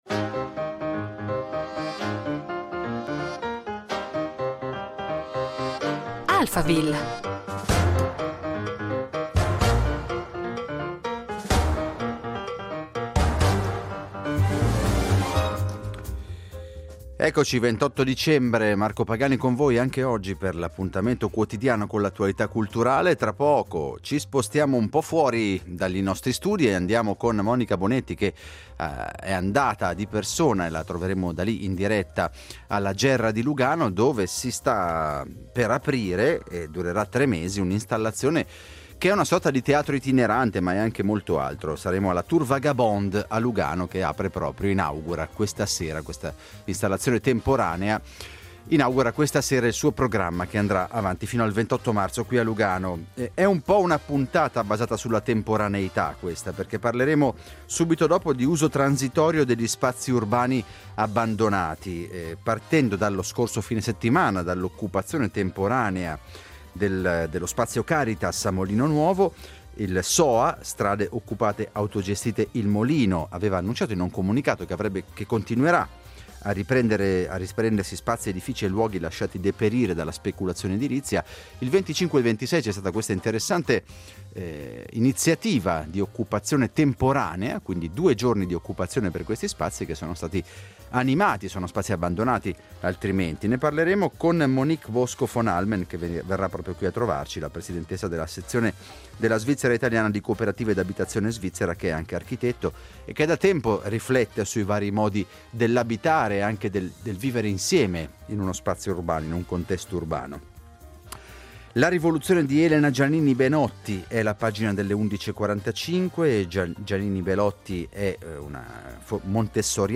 Usciamo idealmente dallo studio di Rete Due a Lugano Besso per trasferirci alla Gerra, sempre a Lugano, dove è “spuntata” una struttura in legno. Di cosa si tratta: è una torre circolare alta 11 metri – che infatti si chiama Tour Vagabonde – e che si ispira all’architettura dei teatri elisabettiani.